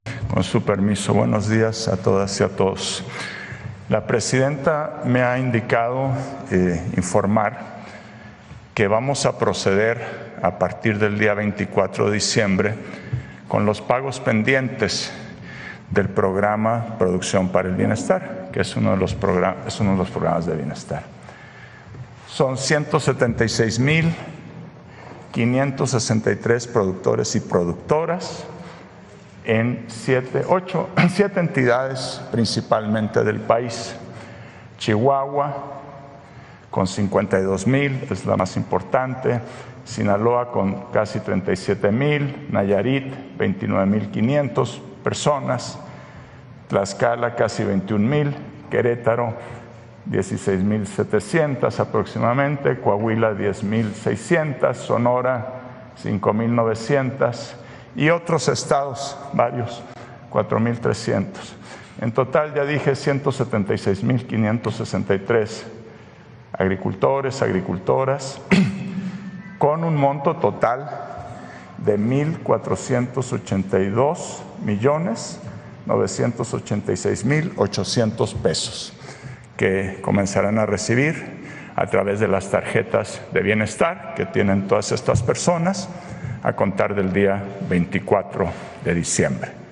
El titular de SADER, Julio Berdegué, anunció los pagos este jueves 19 de diciembre durante la conferencia de prensa matutina de la presidenta Claudia Sheinbaum Pardo.